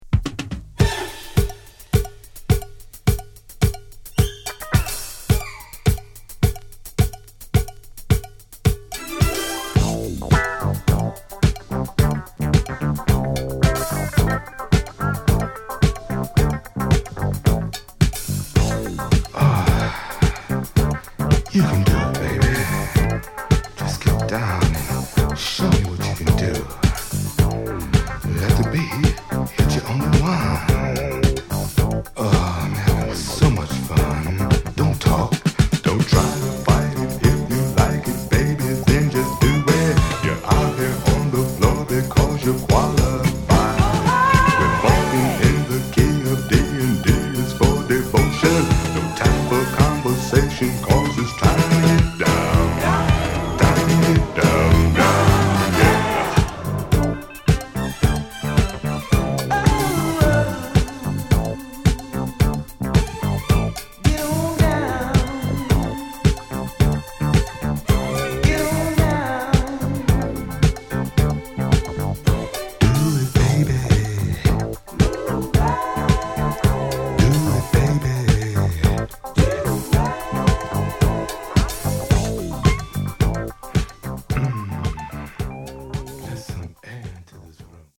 中盤にはパーカッシブブレイクも有！